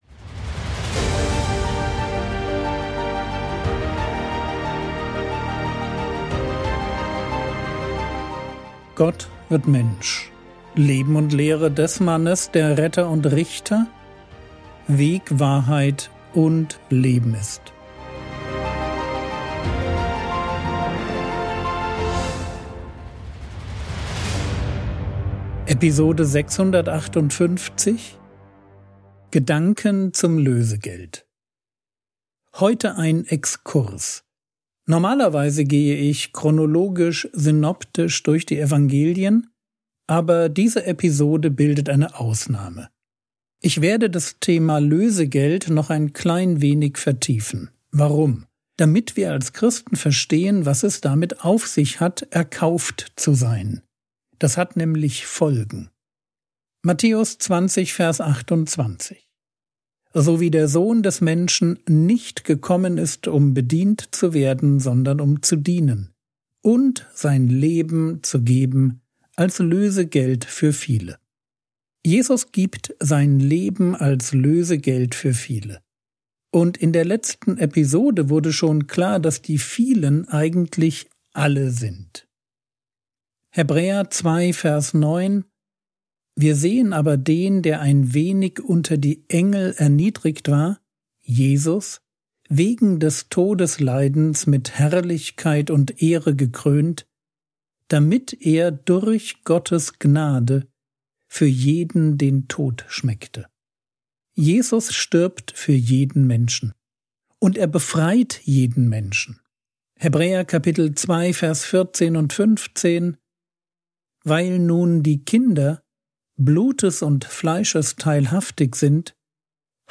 Episode 658 | Jesu Leben und Lehre ~ Frogwords Mini-Predigt Podcast